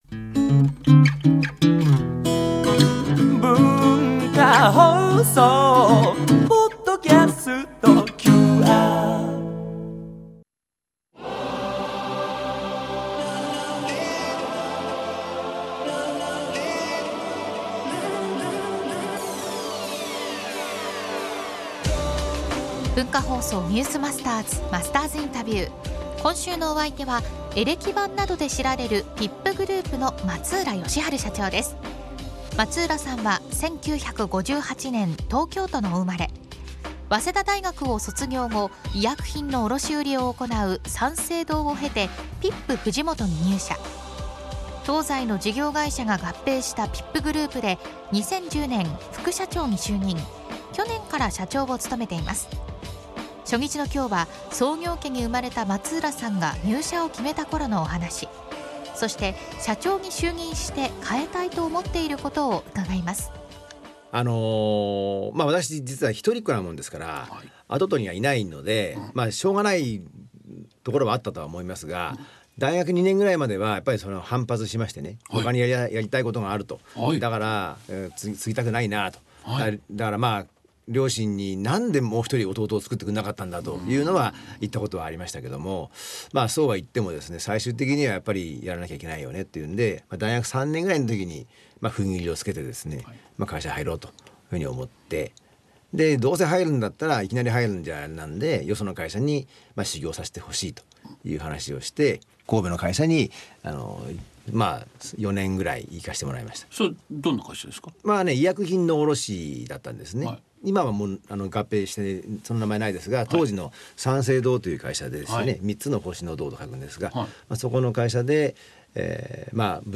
（月）～（金）AM7：00～9：00　文化放送にて生放送！